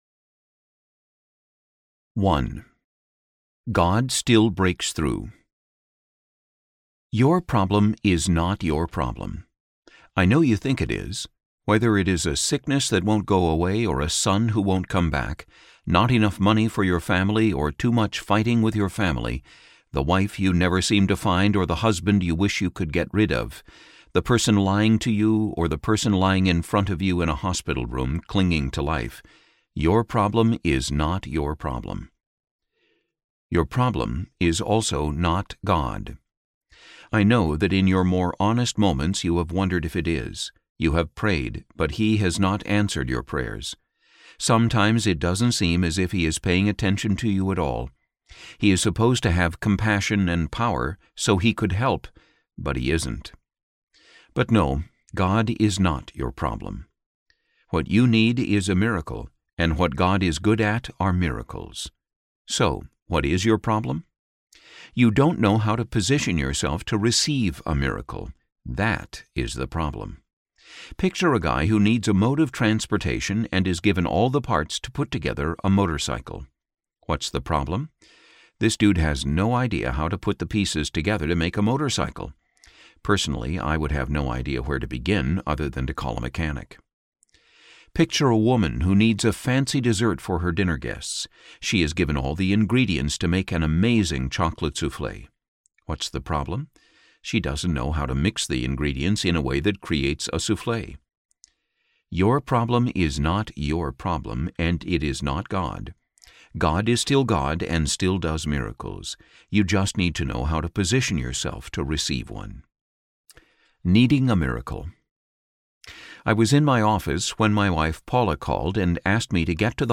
Breakthrough to Your Miracle: Believing God for the Impossible Audiobook
Narrator
4.90 Hrs. – Unabridged